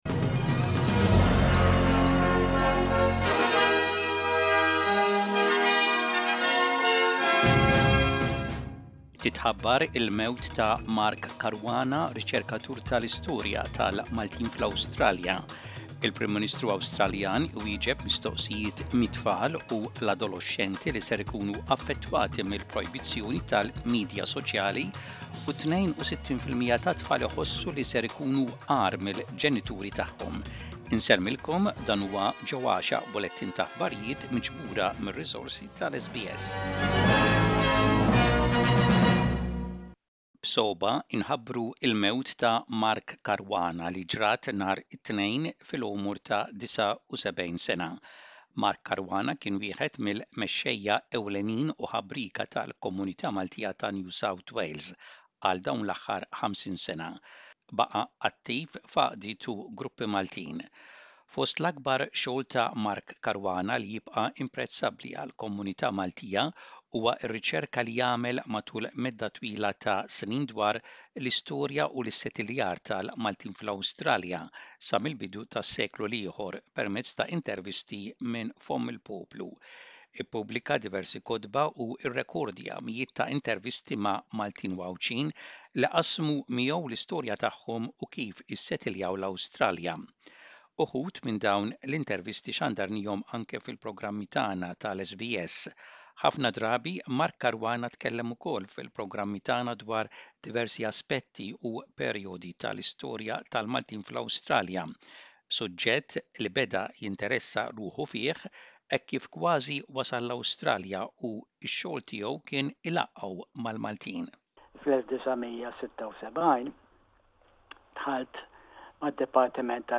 SBS Maltese News - Image SBS Maltese